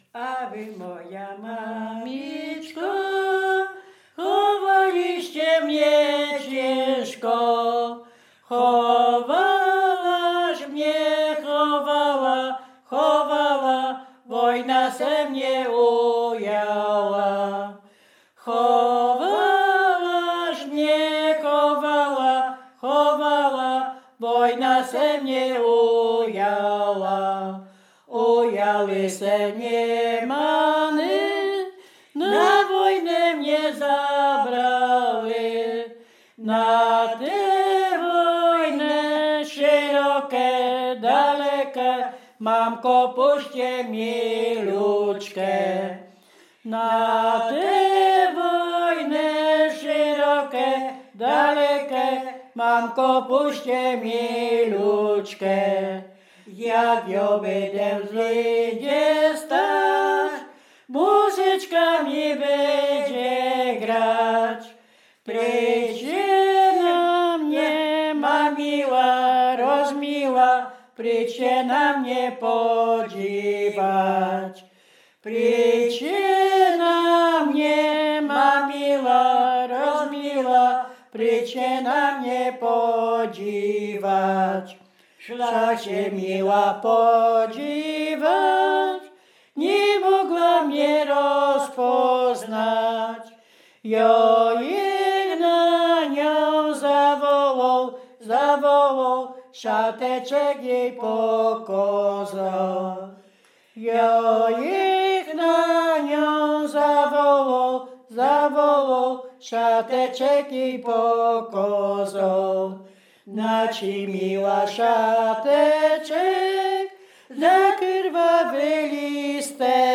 Bukowina Rumuńska
liryczne wojenkowe rekruckie